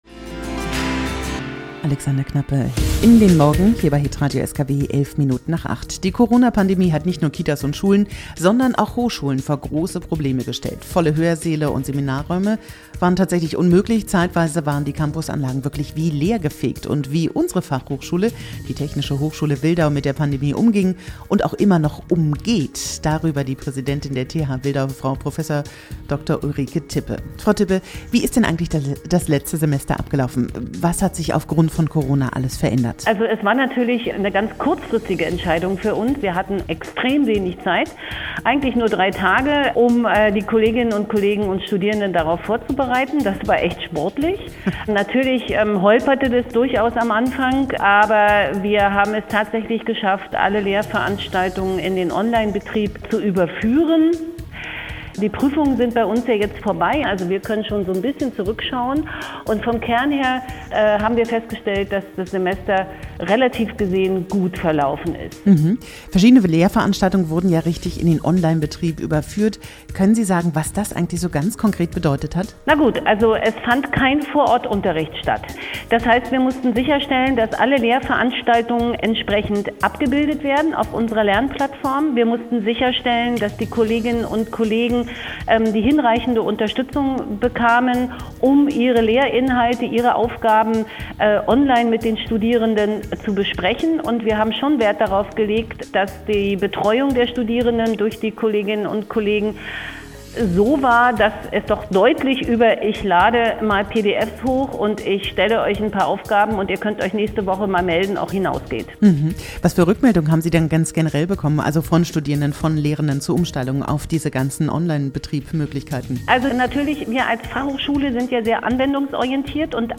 August 2020 | Im Interview